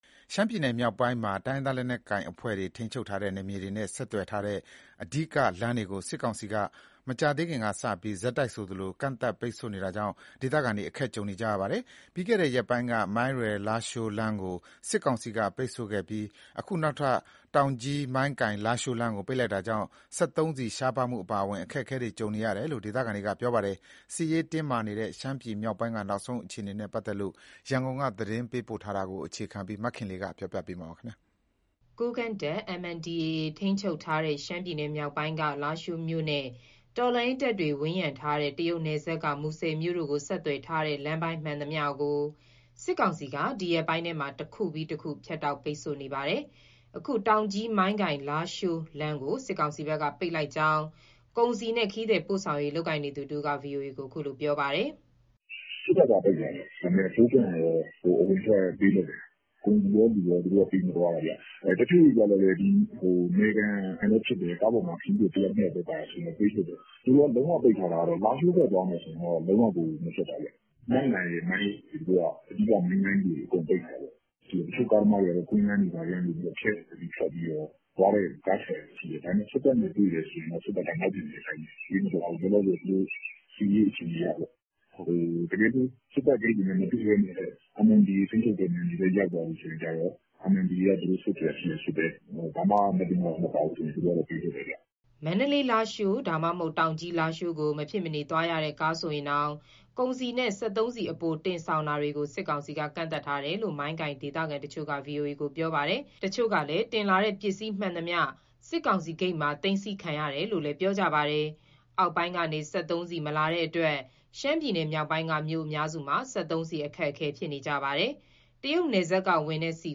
ရှမ်းပြည်မြောက်ပိုင်းမှာ တိုင်းရင်းသားလက်နက်ကိုင် အဖွဲ့တွေ ထိန်းချုပ်ထားတဲ့ နယ်မြေတွေနဲ့ ဆက်သွယ်ထားတဲံ အဓိကလမ်းတွေကို စစ်ကောင်စီက မကြာသေးခင်ကစ ဆက်တိုက်ဆိုသလို ကန့်သတ်ပိတ်ဆို့နေတာကြောင့် ဒေသခံတွေ အခက်ကြုံနေရပါတယ်။ ပြီးခဲ့တဲ့ရက်ပိုင်းက မိုင်းရယ်-လားရှိုး လမ်းကို စစ်ကောင်စီကပိတ်ဆို့ခဲ့ပြီး အခုနောက်ထပ် တောင်ကြီး-မိုင်းကိုင်-လားရှိုးလမ်းကို ပိတ်လိုက်တာကြောင့် စက်သုံးဆီ ရှားပါးမှု အပါအဝင် အခက်အခဲတွေ ကြုံနေရတယ်လို့ ဒေသခံတွေက ပြောပါတယ်။ သတင်းအပြည့်အစုံ ရန်ကုန်က ပေးပို့ထားပါတယ်။